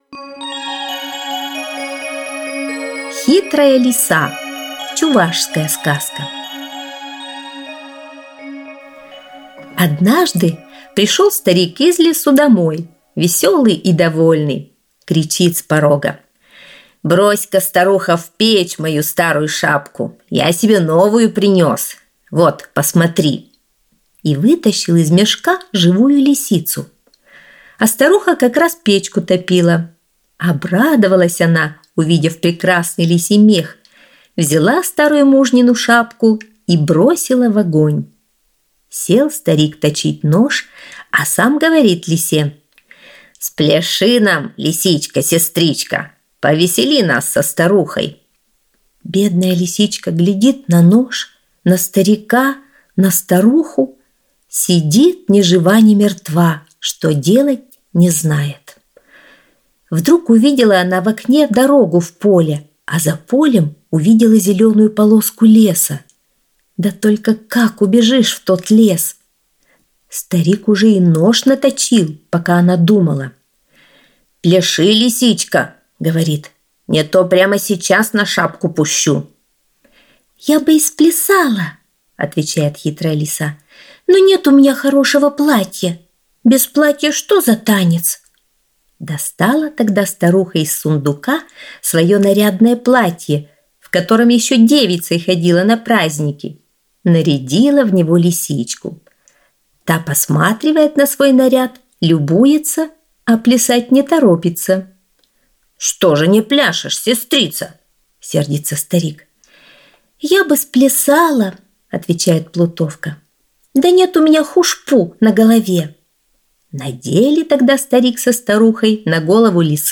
Аудиосказка «Хитрая лиса»